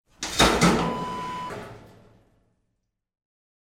Sauna stove door open sound effect .wav #2
Description: The sound of opening the door of a wood-burning sauna stove
A beep sound is embedded in the audio preview file but it is not present in the high resolution downloadable wav file.
Keywords: sauna, wood, burn, burning, woodburning, wood-burning, wood-fired, stove, heater, heating, door, metal, hatch, open, opening
sauna-stove-door-open-preview-2.mp3